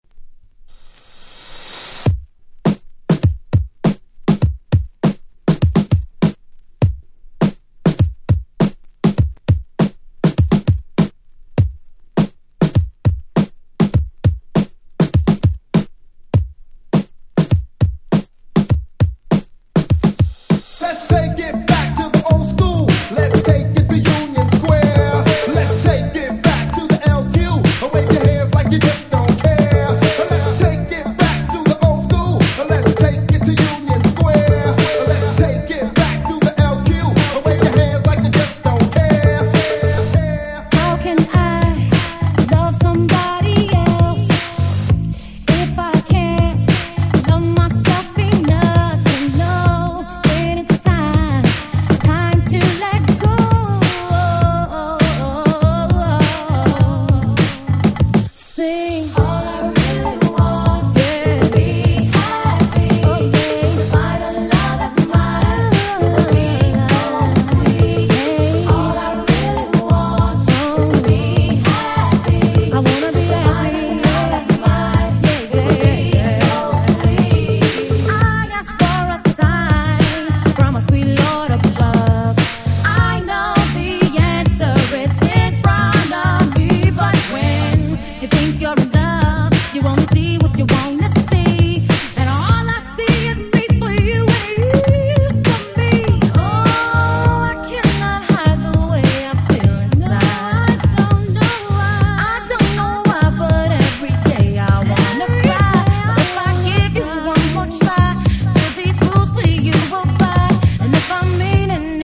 【Media】Vinyl 7'' Single (新品！！)
22’ Super Nice Remix !!